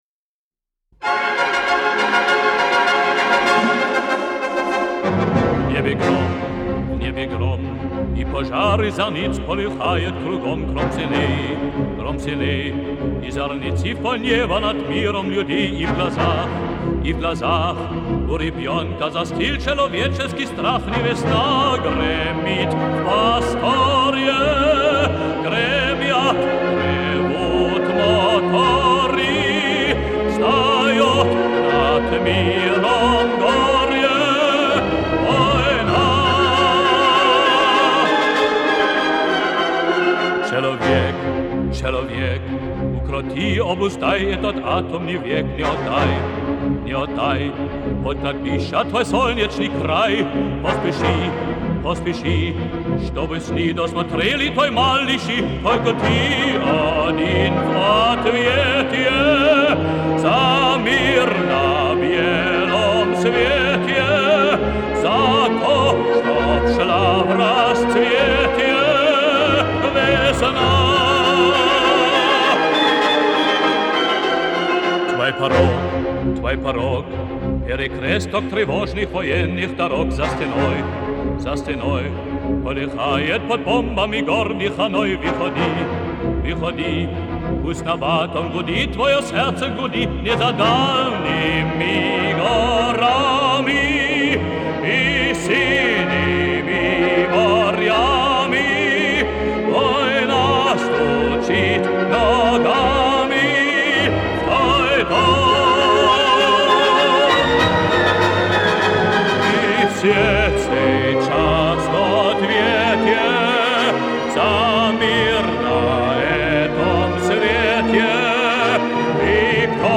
Сильная антивоенная песня-призыв
Исполняет немецкий певец на русском языке.